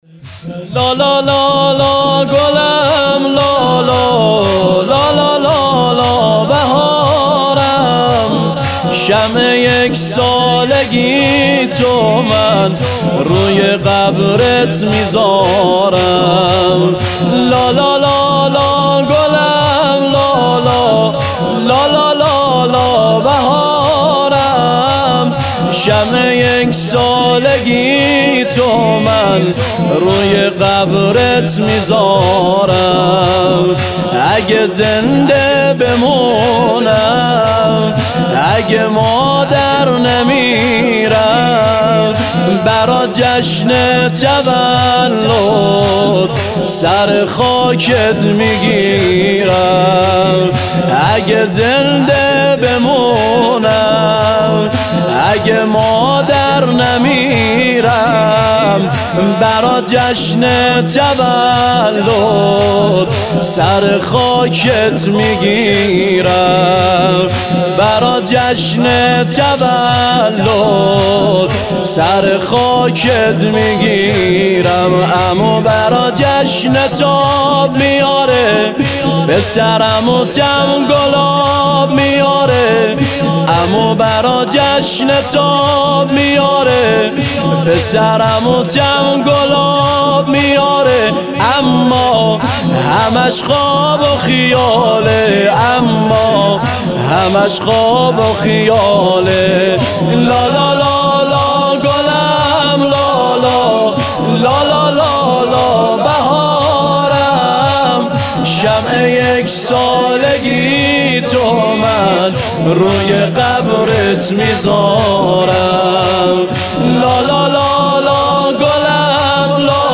محرم 96
نوحه زنجیر زنی نوحه شور